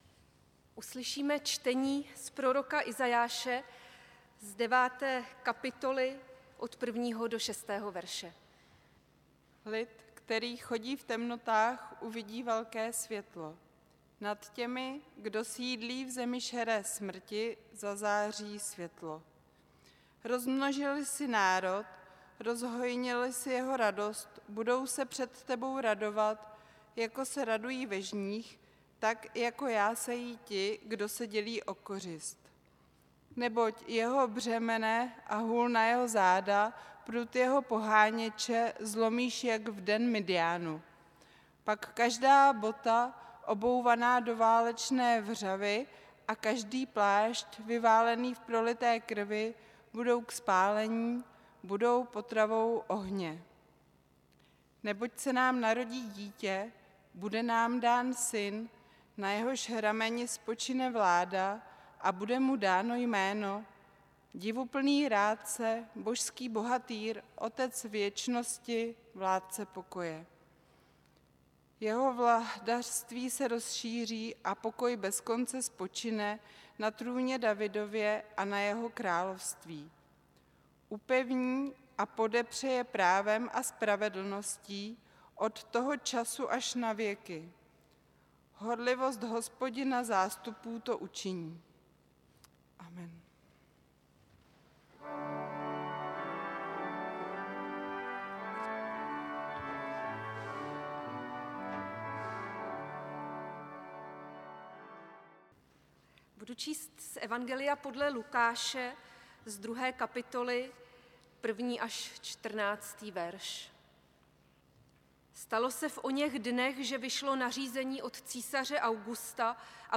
Půlnoční bohoslužba 24. prosince 2025
Děkujeme. záznam kázání Příspěvek byl publikován v rubrice Kázání .